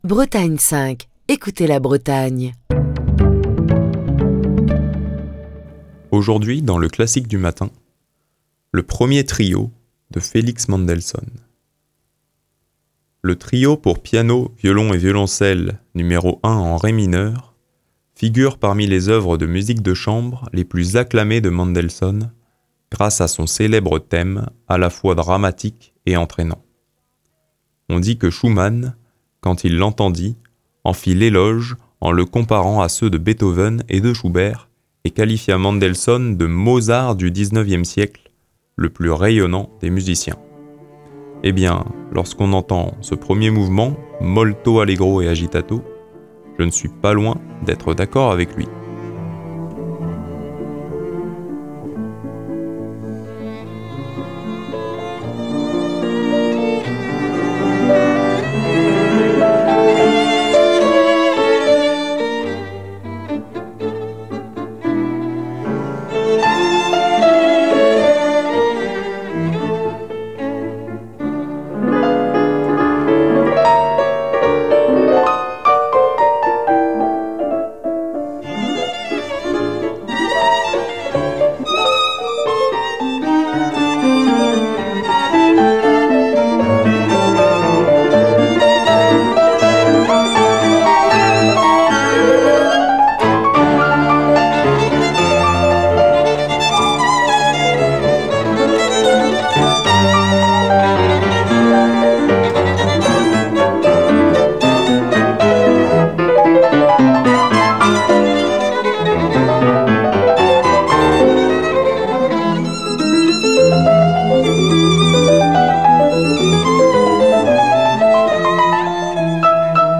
Le Trio pour piano, violon et violoncelle n°1 en ré mineur, figure parmi les œuvres de musique de chambre les plus acclamées de Mendelssohn, grâce à son célèbre thème à la fois dramatique et entrainant.
Le Molto Allegro E Agitato du premier Trio pour piano, violon et violoncelle de Félix Mendelsohnn, est interprété par le Beaux Arts Trio, légendaire trio américain à l'impre
grâce au pianiste Menahem Pressler